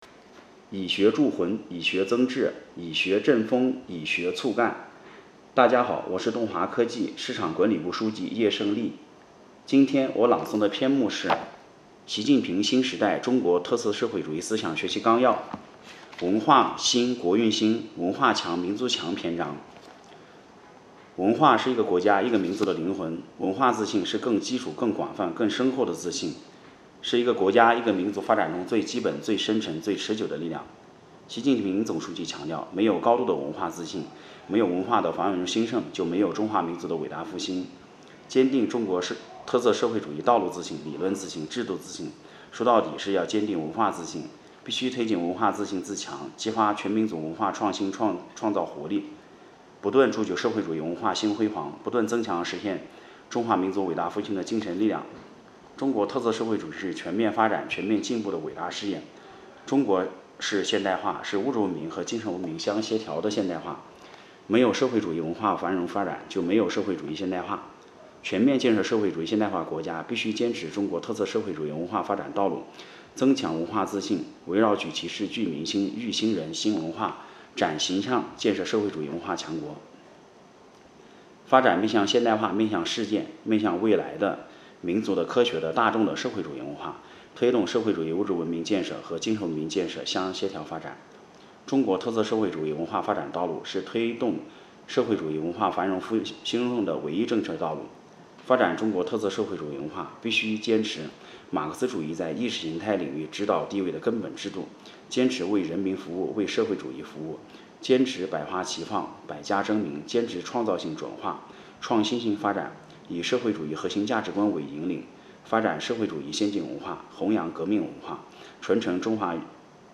诵读人